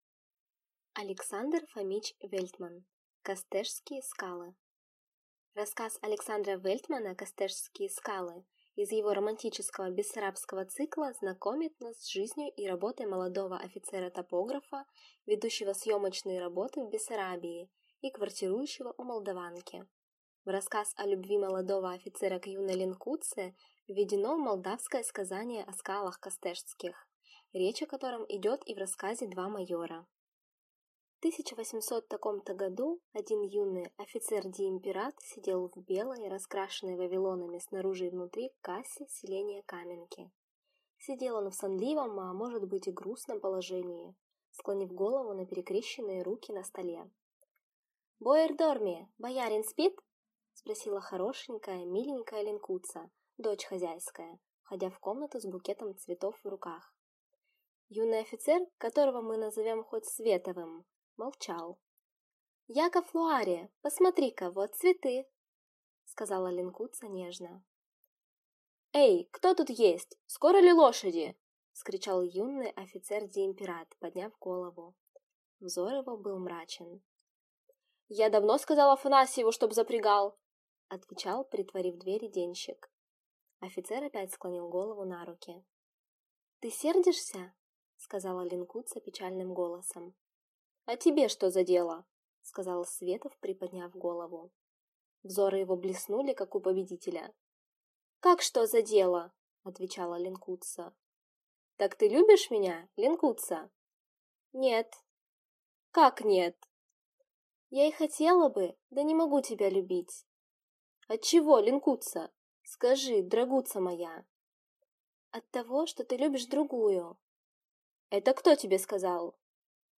Аудиокнига Костешские скалы | Библиотека аудиокниг